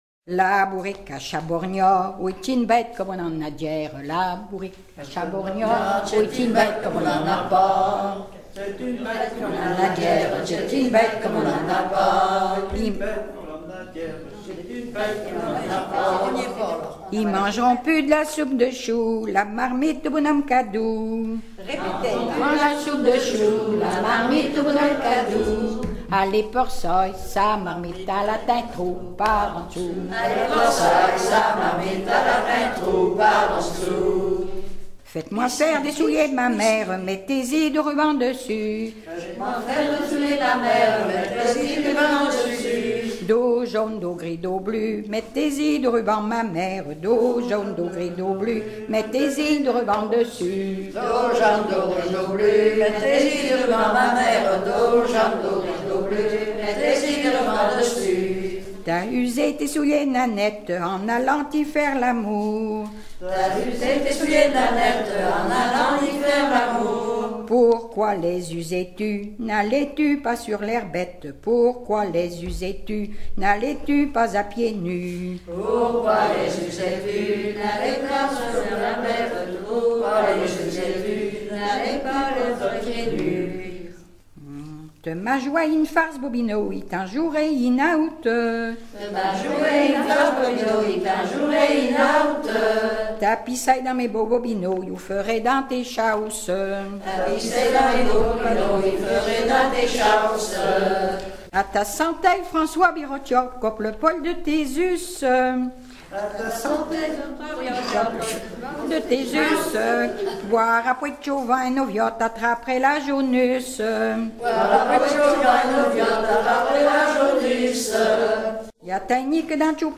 Suite de branles
danse : branle : courante, maraîchine
Pièce musicale éditée